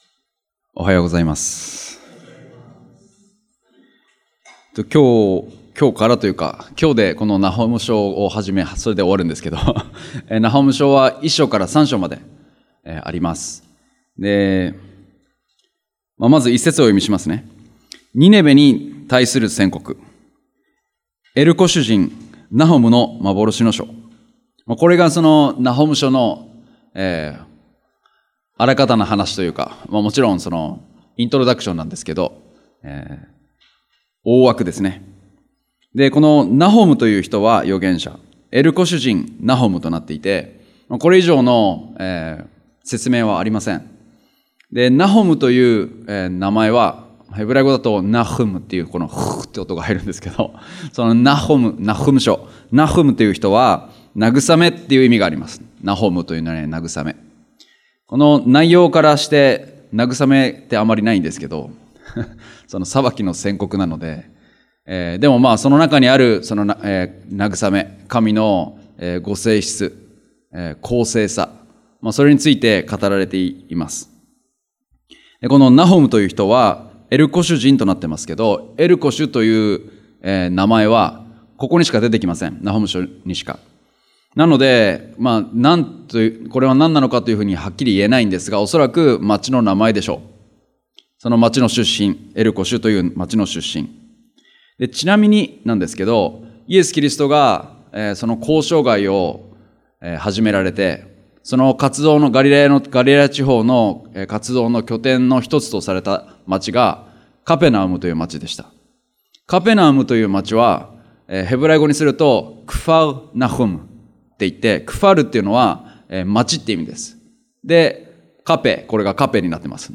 日曜礼拝：ナホム書
礼拝やバイブル・スタディ等でのメッセージを聞くことができます。